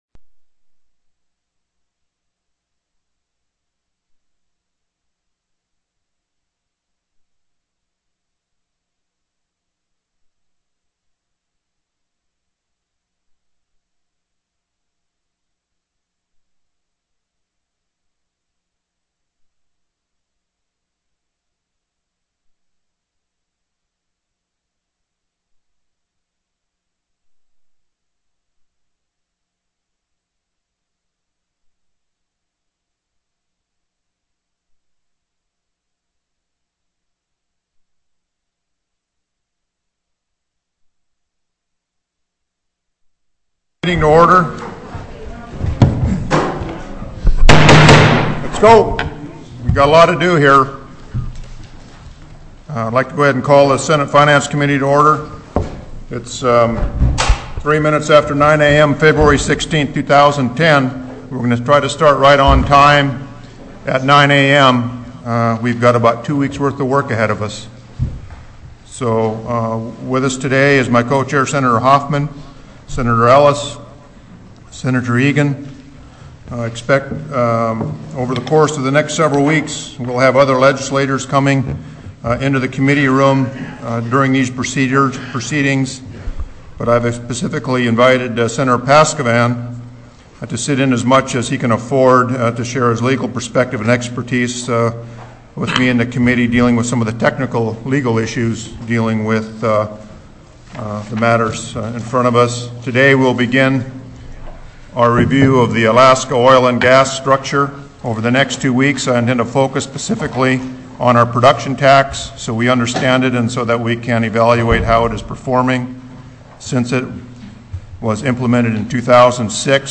Oil & Gas Production Forecast TELECONFERENCED